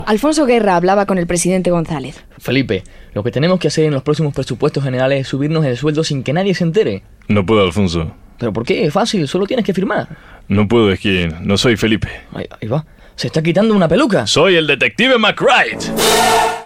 "Sketch" humorístic.
Entreteniment